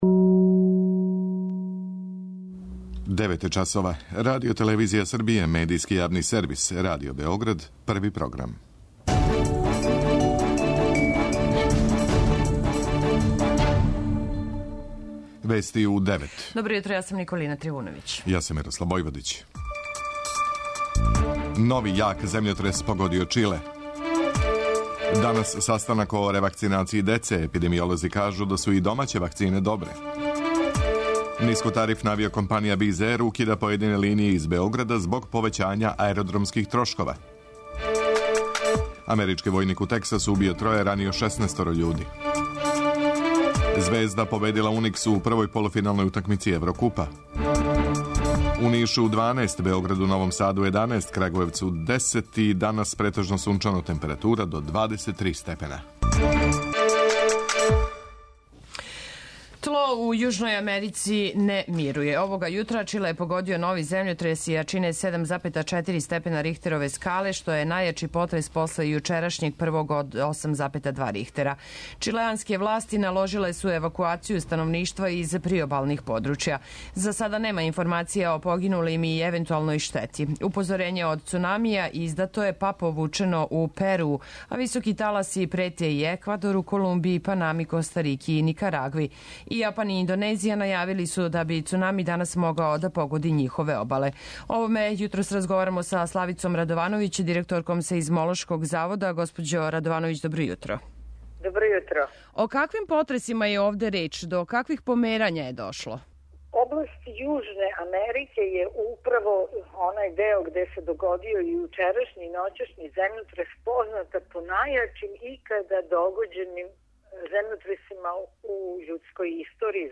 преузми : 10.42 MB Вести у 9 Autor: разни аутори Преглед најважнијиx информација из земље из света.